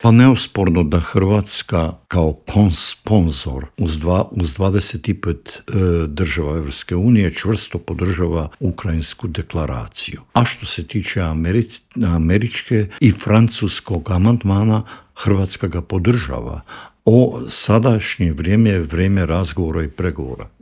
O tome se proteklih dana razgovaralo i u Vladi, a posebni savjetnik premijera Andreja Plenkovića i bivši ministar vanjskih i europskih poslova Mate Granić u Intervjuu Media servisa rekao je da je Ured predsjednika informiran o svemu što radi Vlada RH.